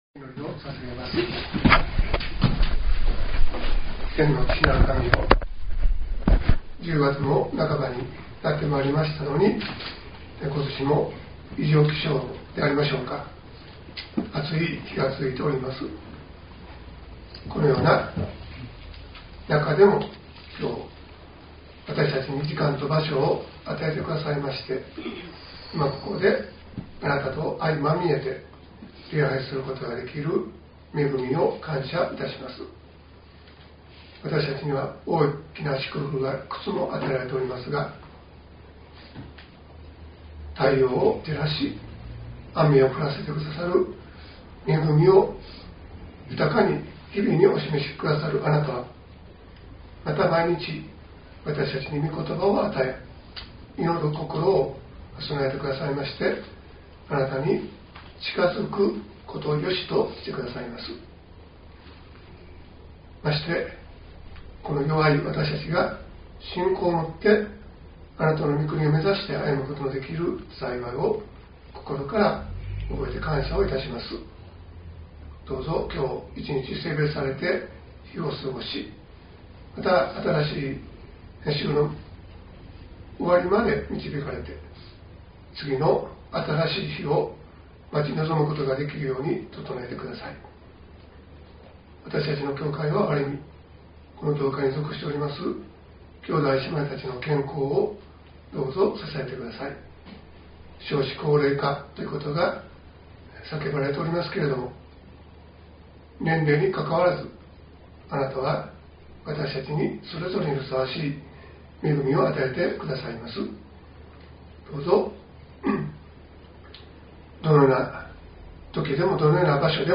.mp3 ←クリックして説教をお聴きください。